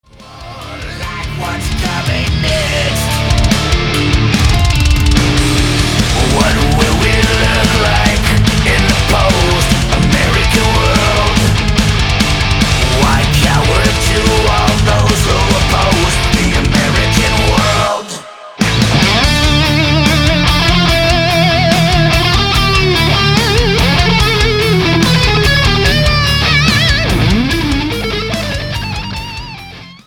Studio: Lattitude South Studios, Leiper's Fork, Tennessee
Genre: Thrash Metal, Heavy Metal